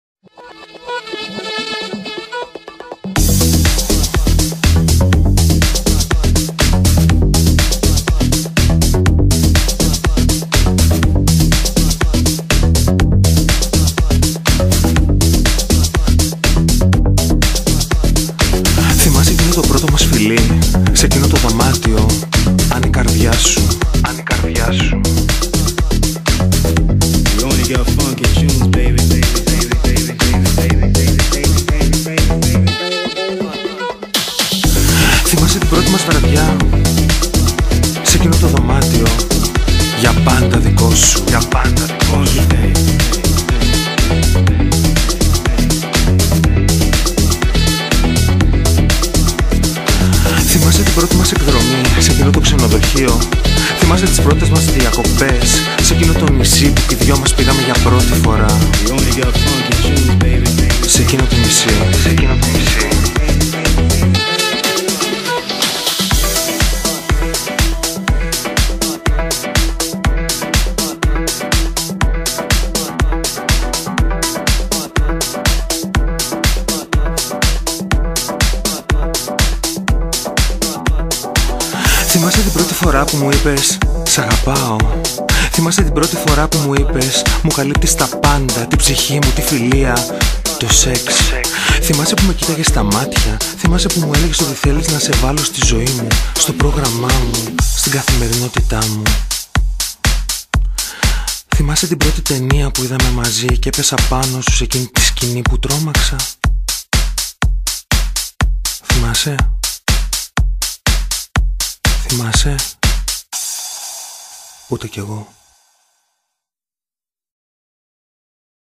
House